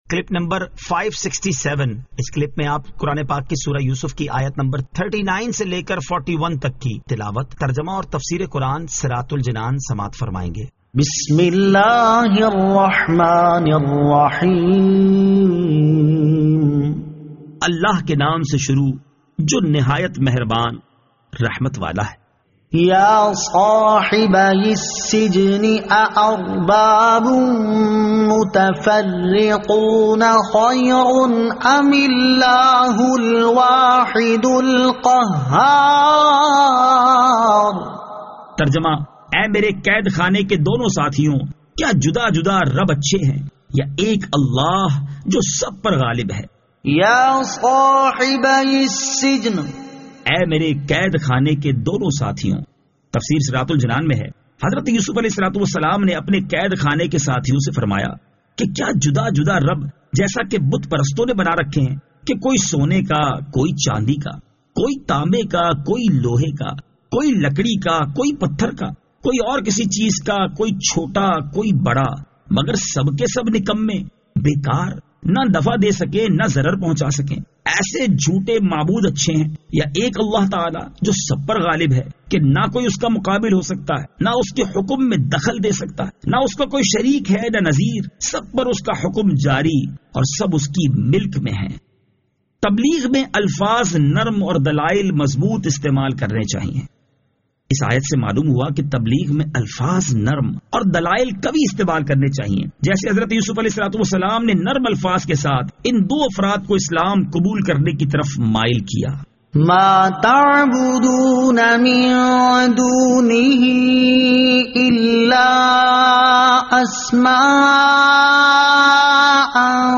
Surah Yusuf Ayat 39 To 41 Tilawat , Tarjama , Tafseer